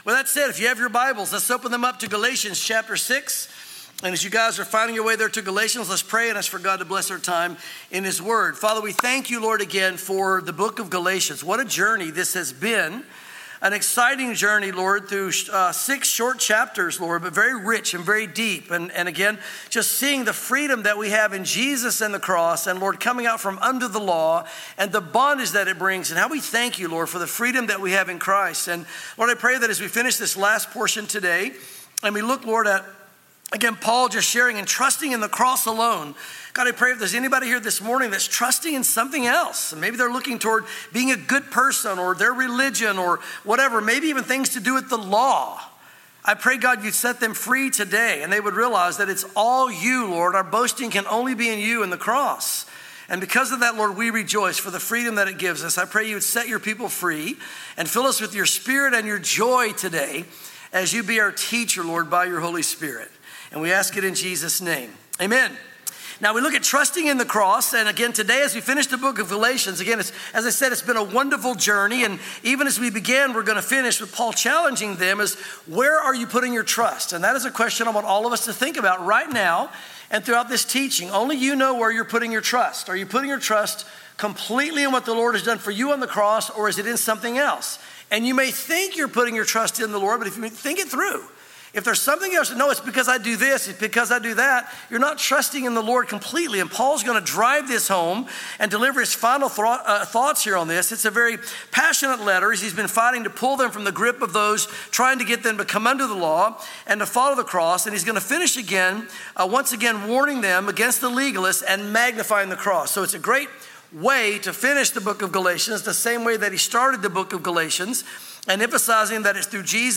sermons Galatians 6:11-18 | Trusting In The Cross Alone